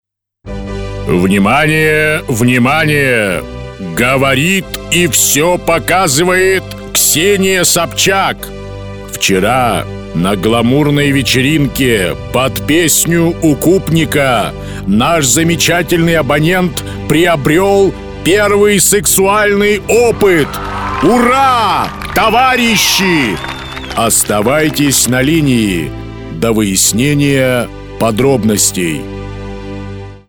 Parodiya_na_Levitana.mp3